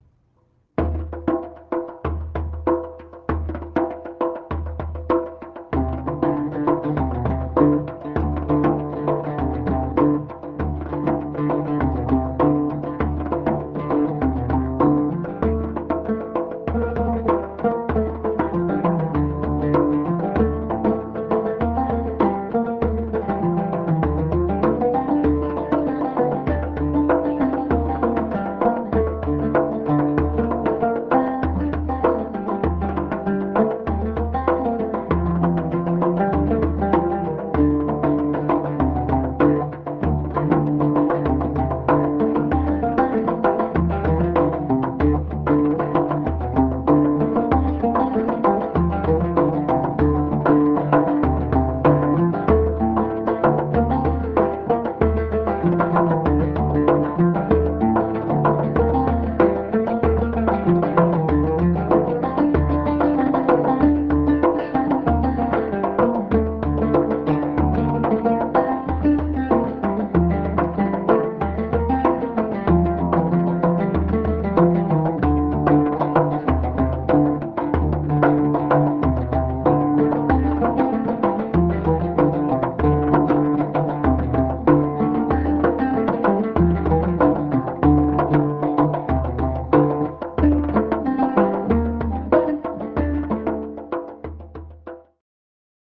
oud (Middle Eastern lute) and nay (cane flute)
"Fast" Chifte-telli